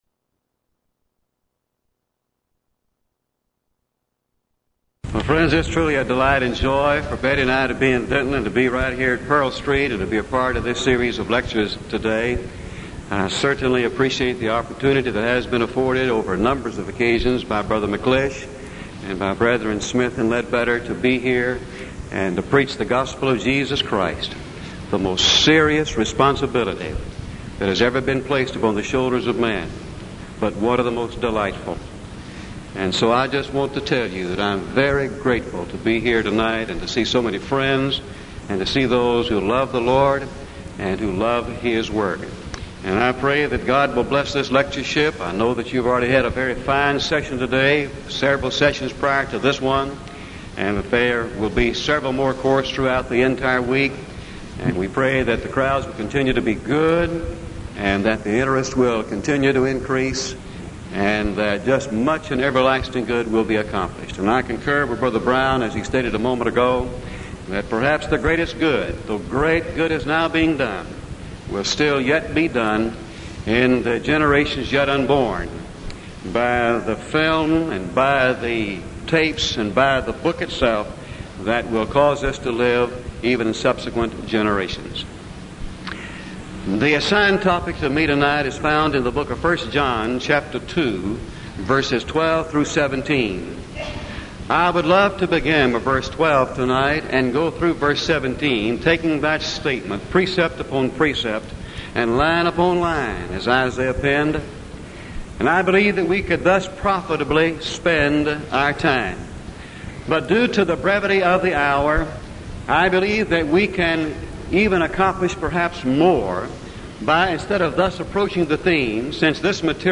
Event: 1987 Denton Lectures Theme/Title: Studies In I, II, III John
lecture